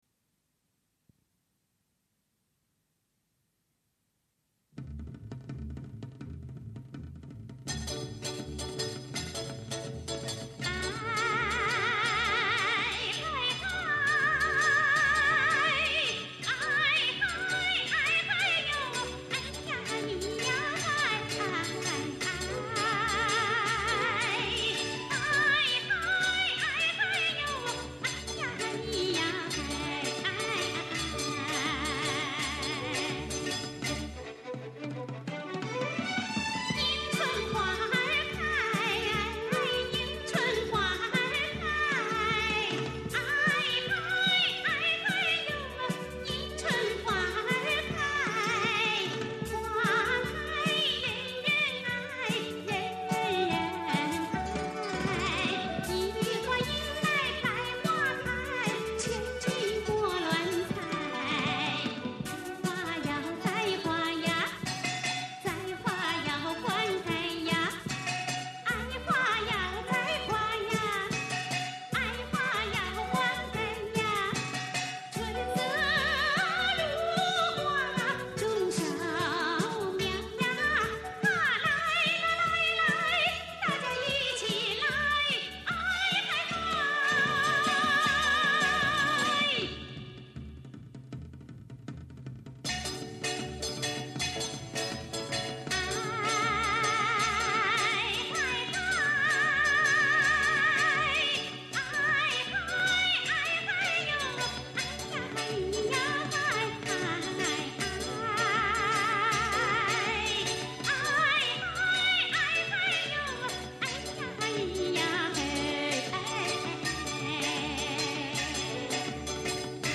资料类型 盒式录音带